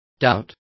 Complete with pronunciation of the translation of doubt.